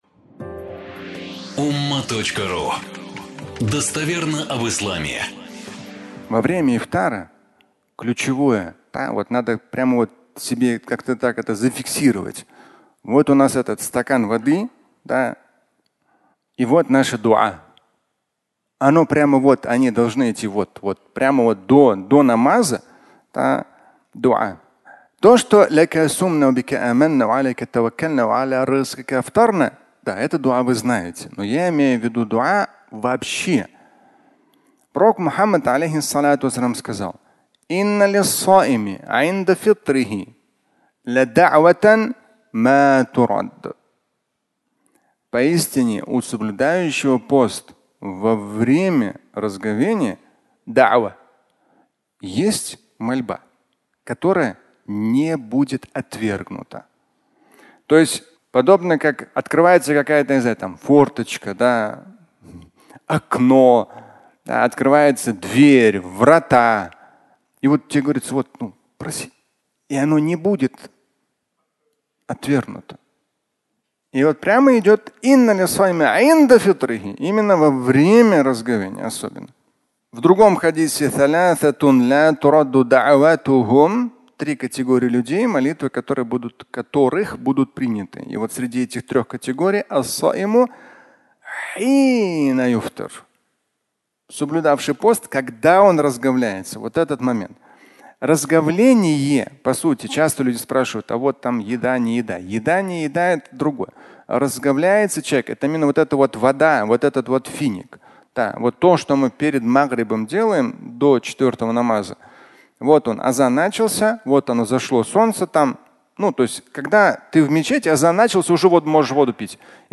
Мольба и пост (аудиолекция)
Фрагмент пятничной лекции, в котором Шамиль Аляутдинов говорит о дуа во время ифтара, цитируя хадис на эту тему.